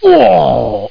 copfaint.mp3